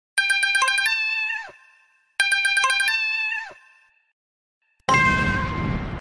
Kitten Meow Ringtone Messenger 🐱😁 sound effects free download